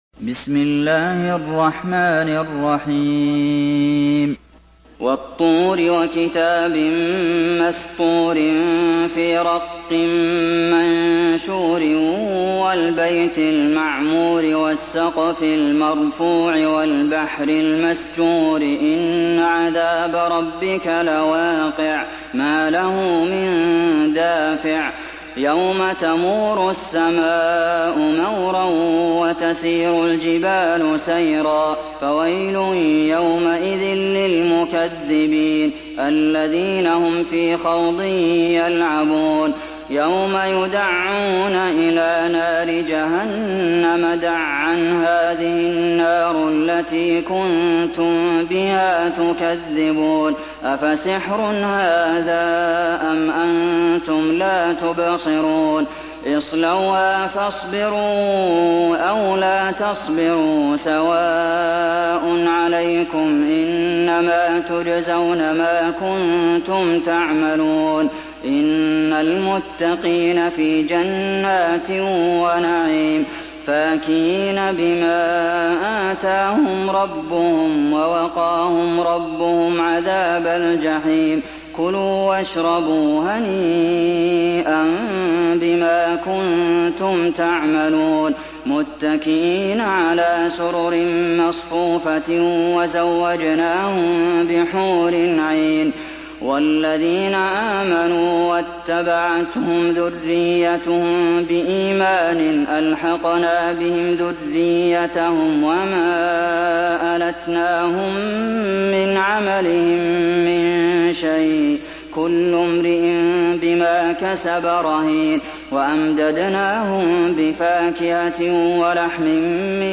سورة الطور MP3 بصوت عبد المحسن القاسم برواية حفص